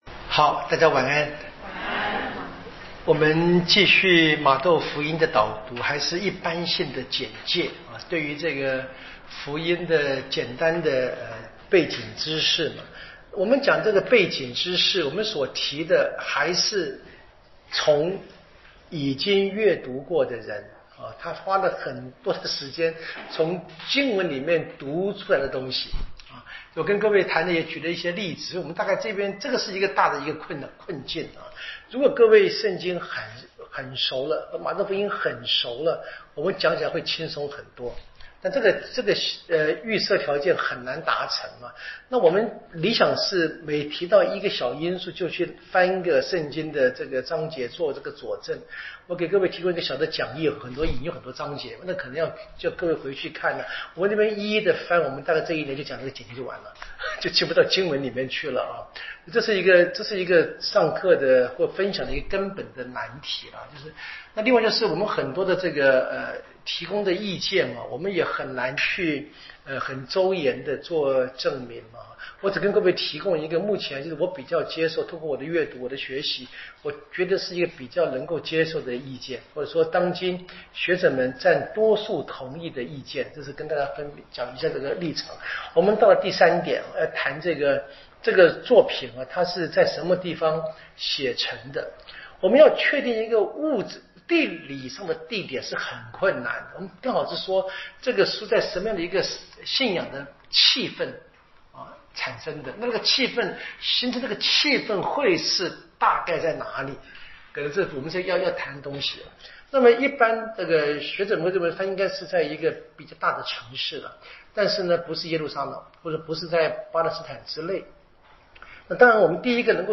【圣经讲座】